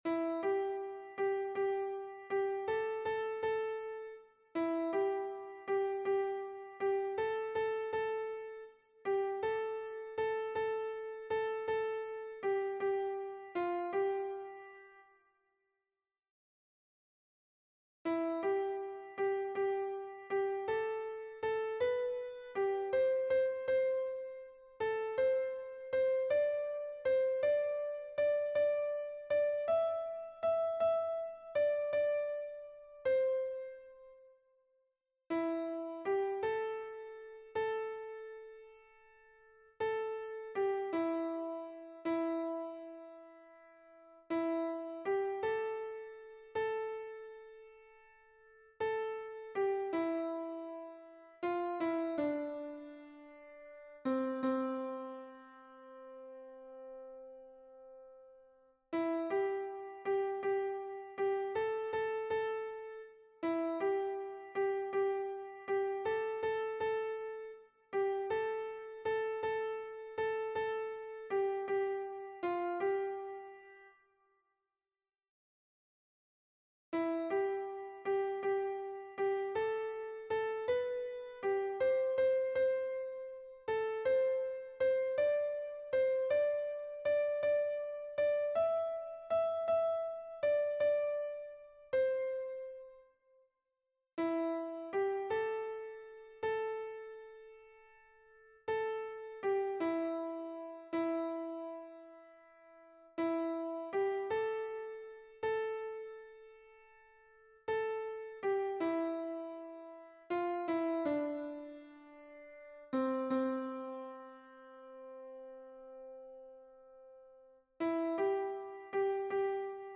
Voix seules